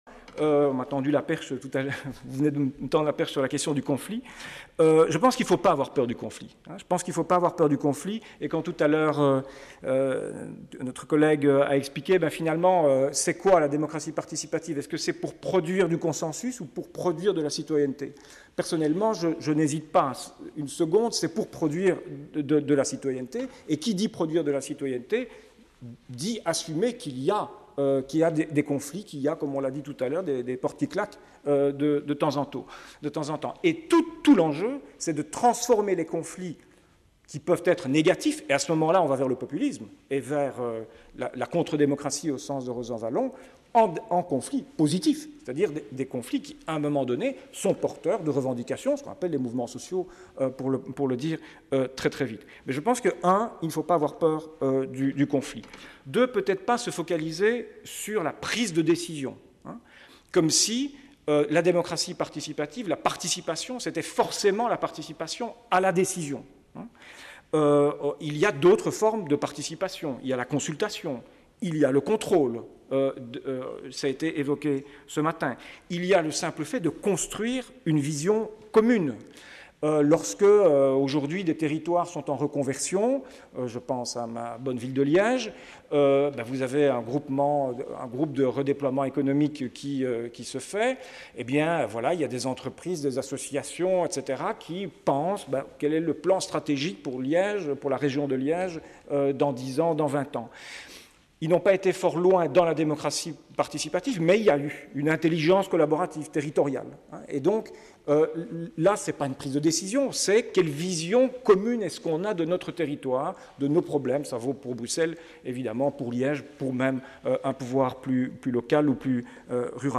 Colloque 23/01/2014 : SC 2